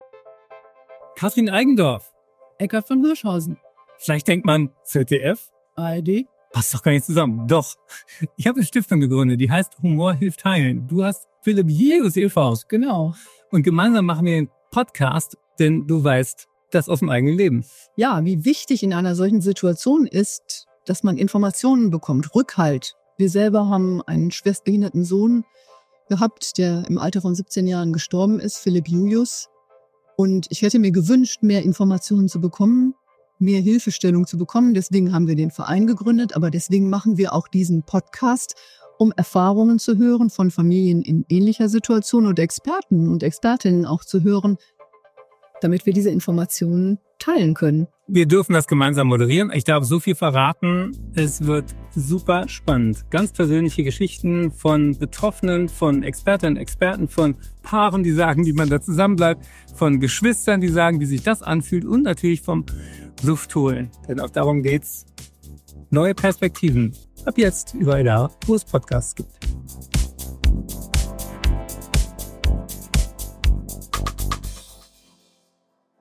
Trailer zum Podcast